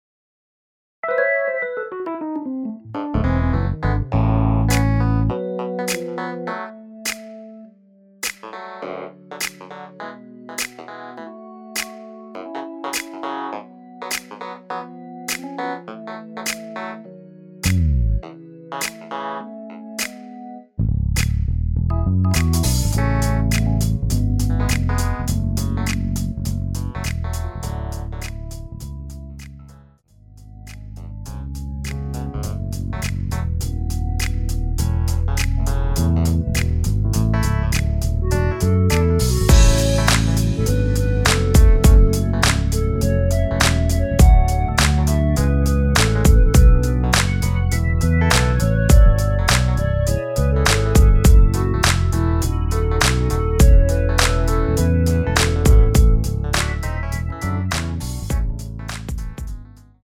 MR은 2번만 하고 노래 하기 편하게 엔딩을 만들었습니다.(본문의 가사와 코러스 MR 미리듣기 확인)
원키에서(-3)내린 멜로디 포함된 MR입니다.
멜로디 MR이라고 합니다.
앞부분30초, 뒷부분30초씩 편집해서 올려 드리고 있습니다.
중간에 음이 끈어지고 다시 나오는 이유는